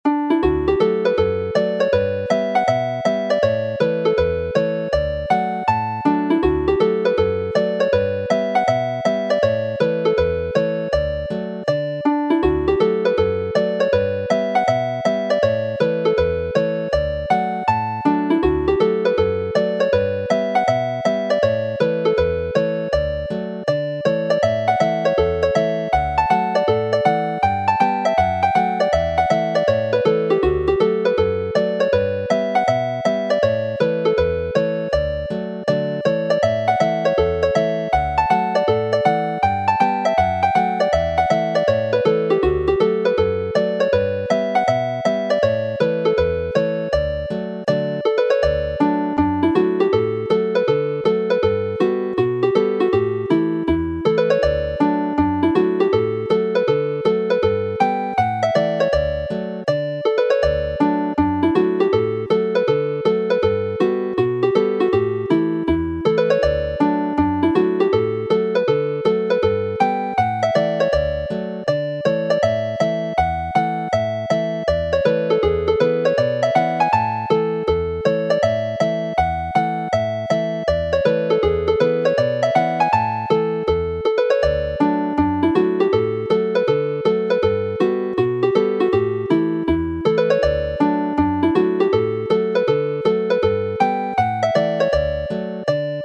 Play the hornpipe set